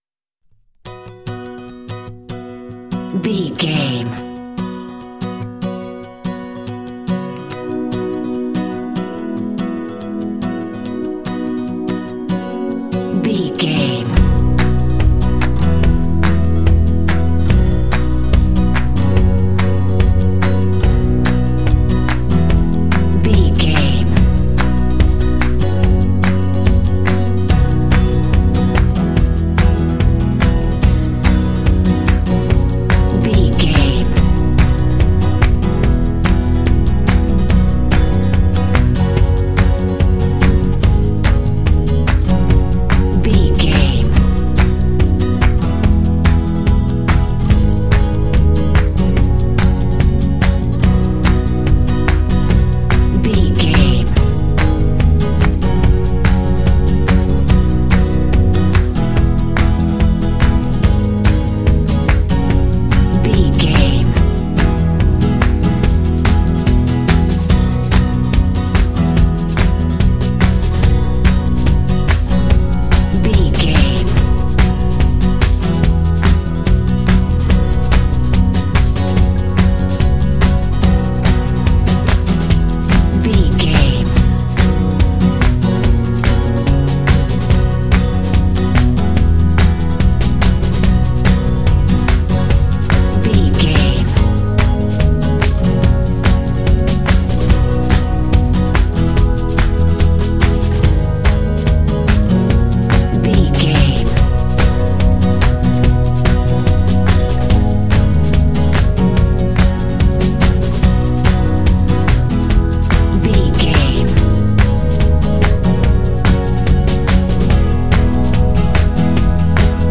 Uplifting
Ionian/Major
Fast
happy
energetic
acoustic guitar
synthesiser
drums
bass guitar
percussion
alternative rock
pop
indie